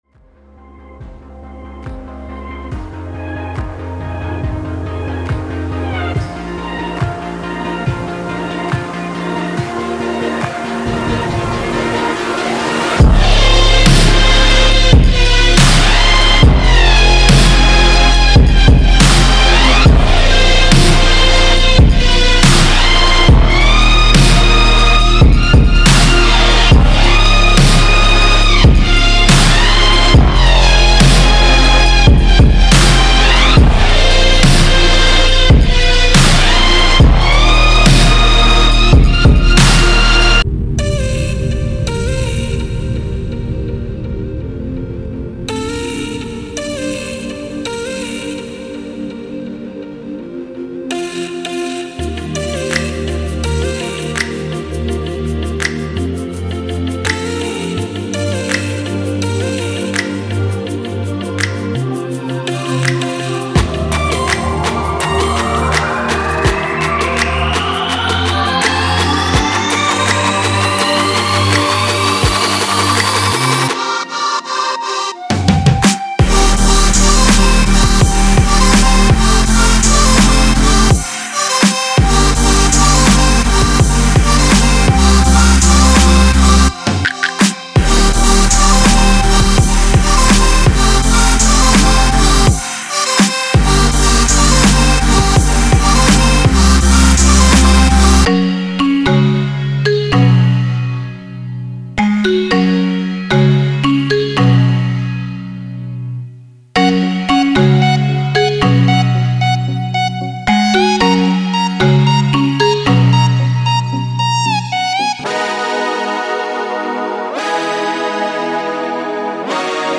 Future Bass音色预置
– 115个Xfer血清预设
– 15个现代人声排骨